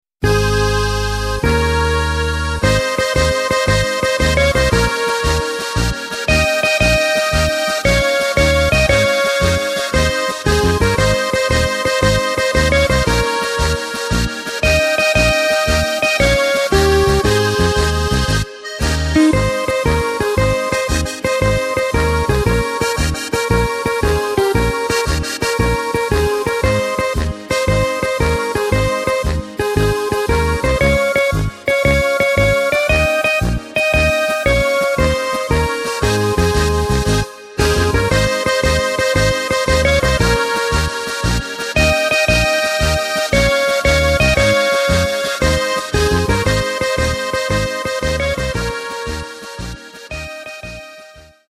Takt:          2/4
Tempo:         100.00
Tonart:            Ab